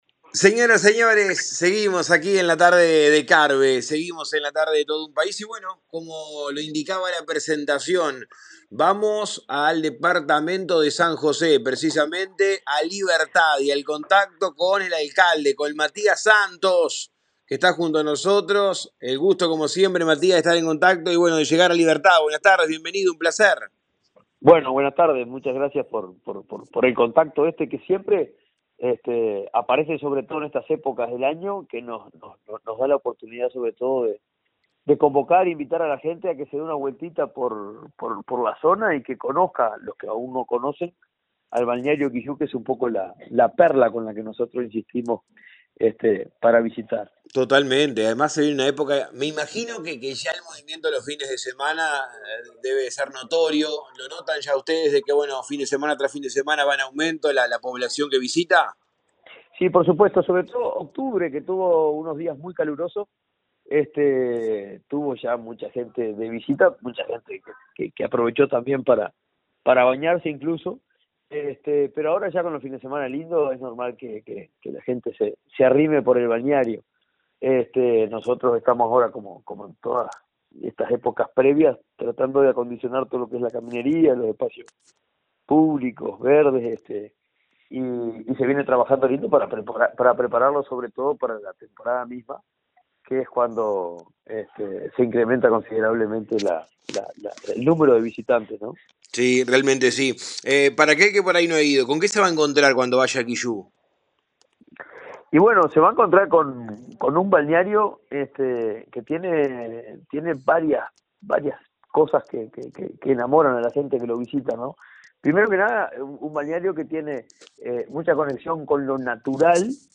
Como cada verano el municipio de Libertad trabaja preparando el balneario Kiyú, que forma parte de su jurisdicción. El alcalde Matias Santos dialogó con Todo Un País y contó cómo trabajan en las costas de Kiyú para recibir a los turistas.